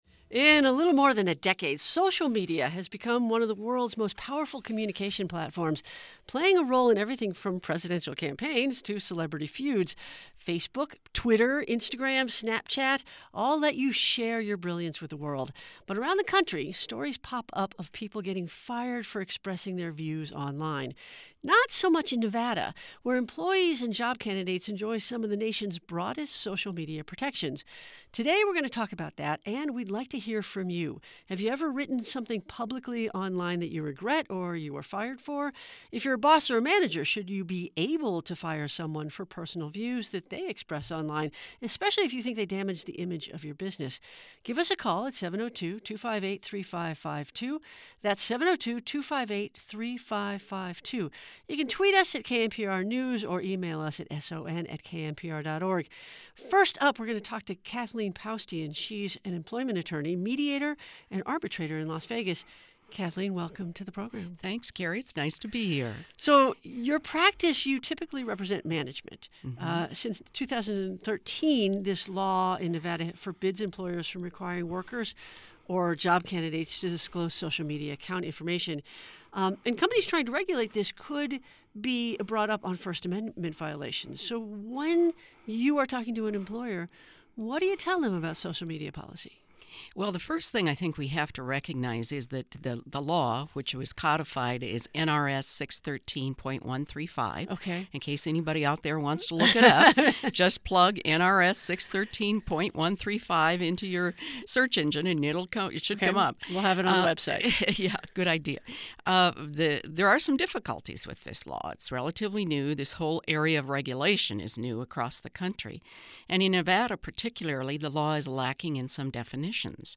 Click below to listen as various questions were asked and answered.